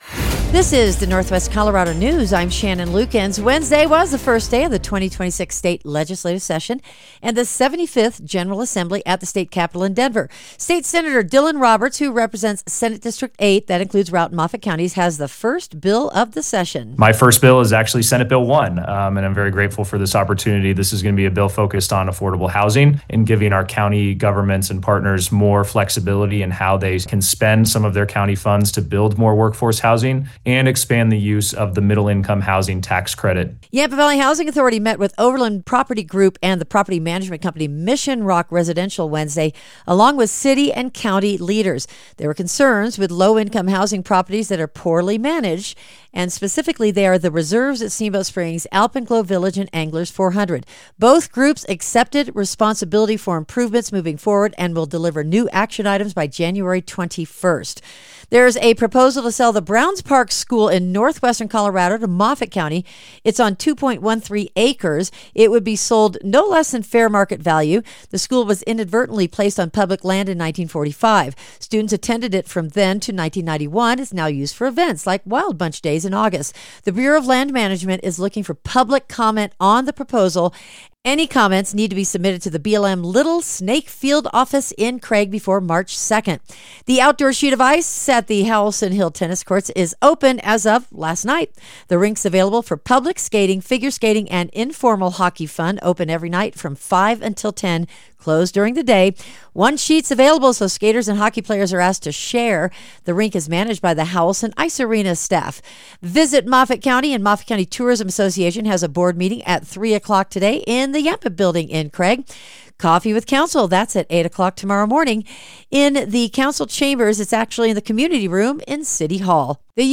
Northwest Colorado News for Thursday, Jan. 15, 2026